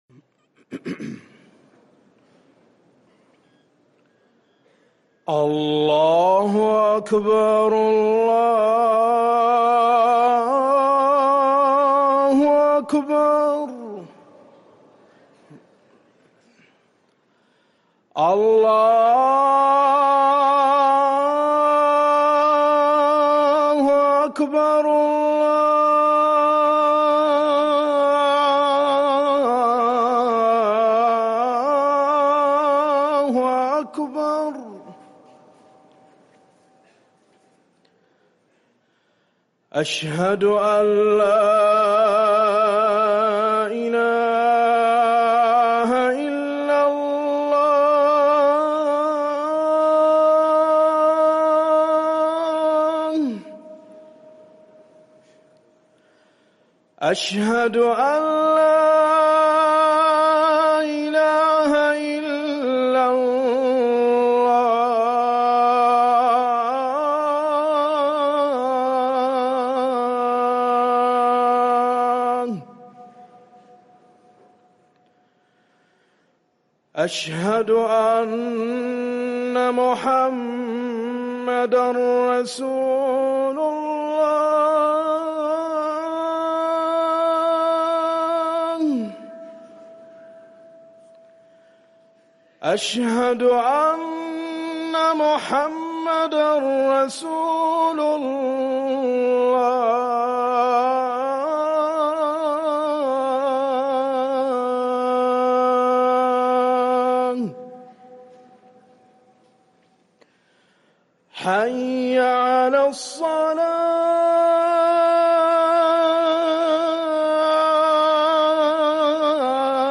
اذان المغرب
ركن الأذان